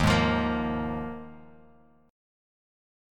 D#sus2sus4 chord